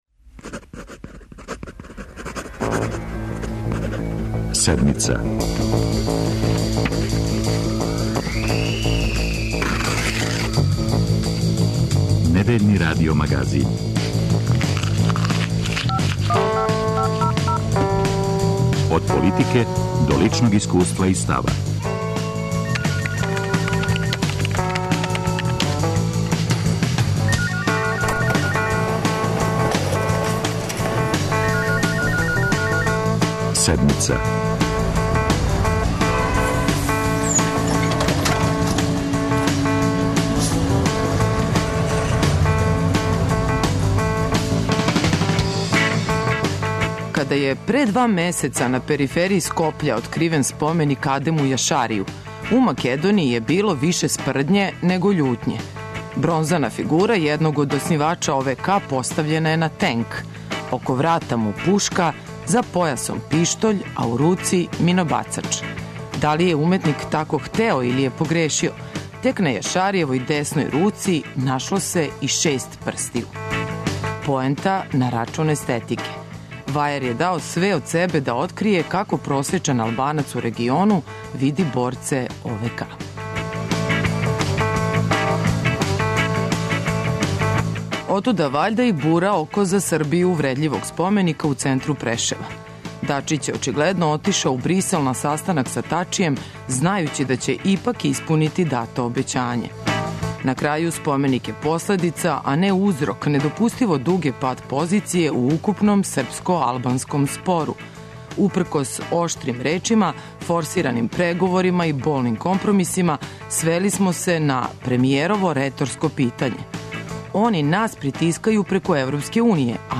О југу Србије и спору тамошњих лидера са Београдом, али и о (не)стабилности владајуће коалиције, ванредним изборима и односу са бившим партнерима демократама - за Седмицу говори потпредседник Владе Расим Љајић.